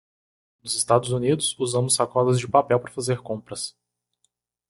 Pronounced as (IPA)
/uˈzɐ̃.mus/